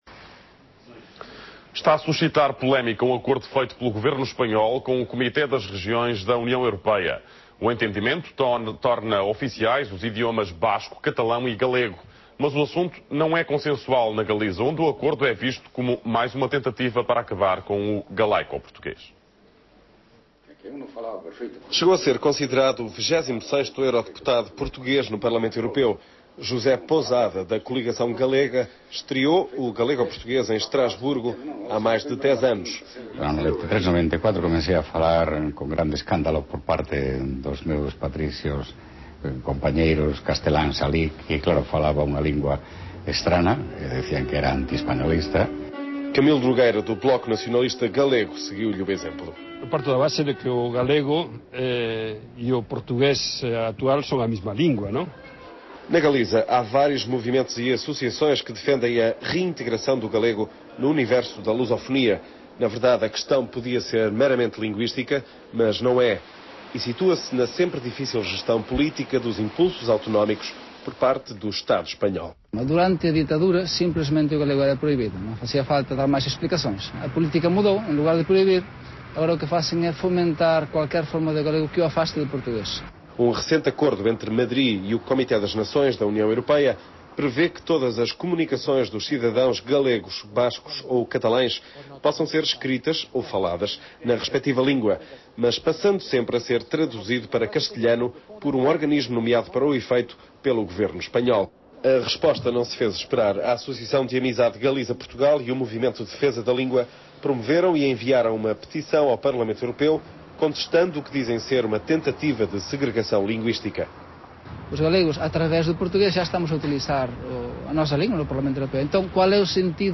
Emissão: Rádio Televisão Portuguesa (RTP e RPT Internacional), Jornal da Tarde.
Lugar da realização: Diversos locais da Galiza e Estrasburgo.
Áudio: Extraído do arquivo VOB original (Dolby AC) com ac3decode (gratuíto); arquivo WAV resultante convertido com dbPowerAmp (gratuíto) a 24 Kbps, 16 Khz, mono.